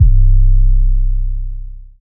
DDW6 808 1.wav